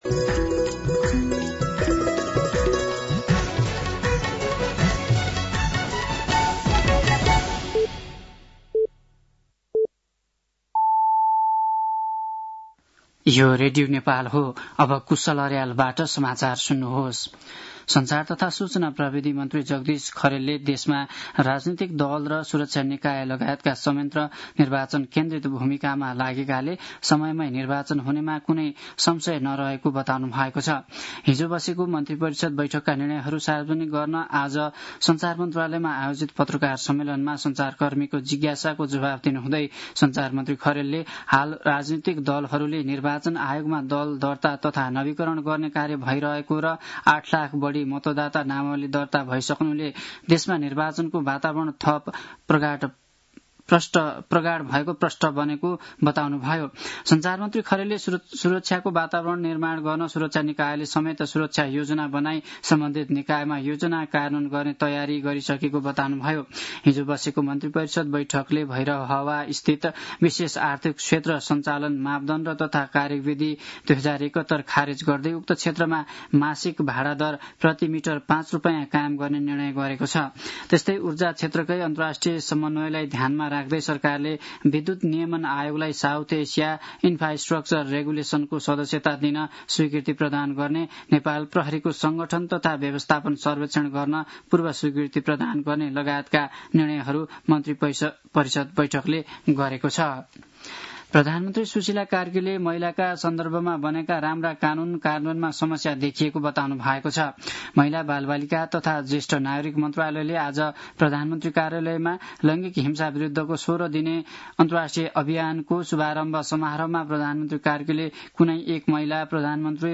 साँझ ५ बजेको नेपाली समाचार : ९ मंसिर , २०८२